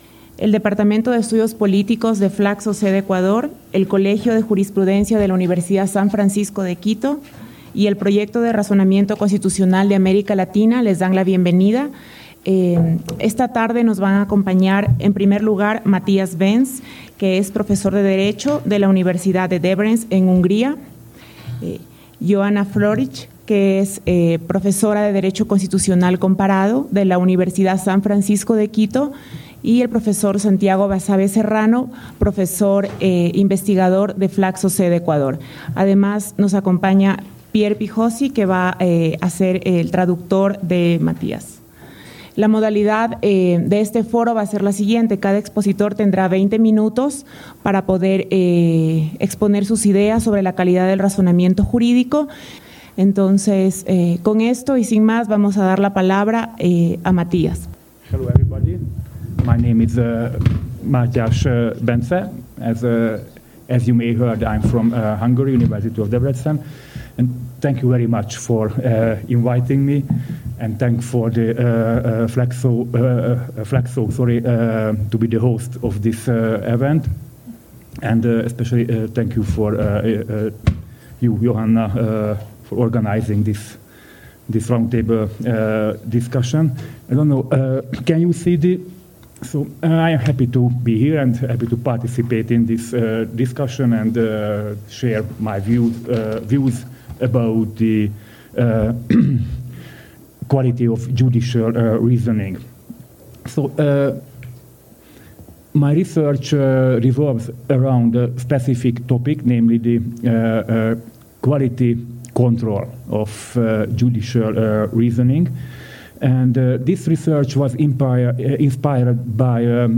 Foro ¿cómo medir la calidad del razonamiento jurídico?